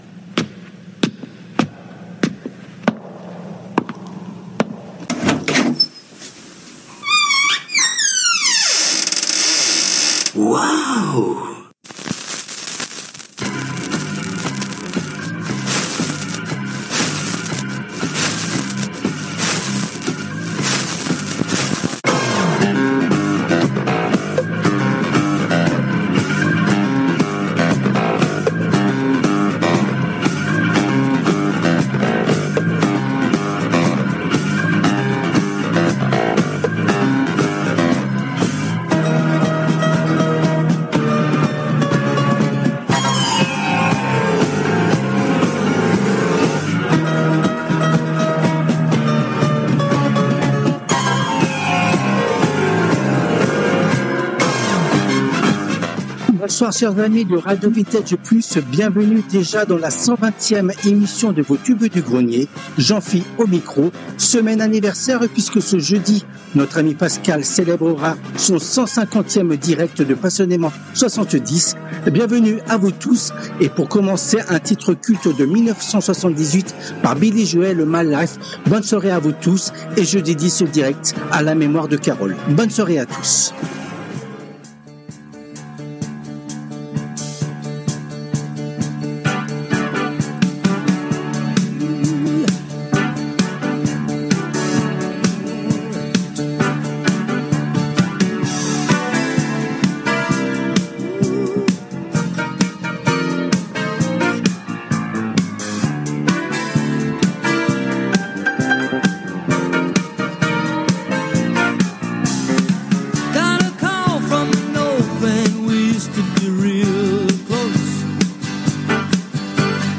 Elle a été diffusée en direct le mardi 04 février 2025 à 19h depuis les studios de RADIO RV+ à PARIS .
Les Tubes du Grenier Les Tubes connus ou oubliés des 60's, 70's et 80's